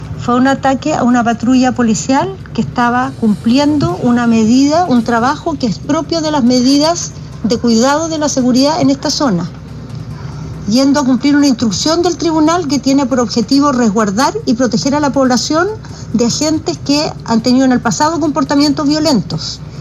Esta hipótesis, sin estar confirmada de manera oficial, coincide con las palabras de la ministra de Interior, Carolina Tohá, quien aún conmovida por los hechos, describió este ataque como inédito.